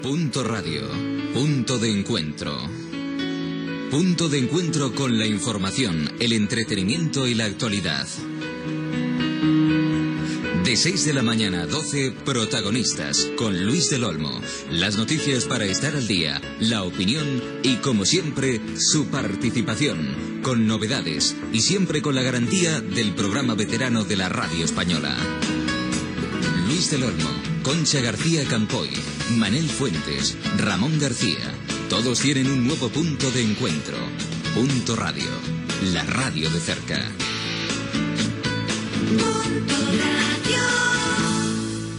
Promoció de la programació de l'emissora
Banda FM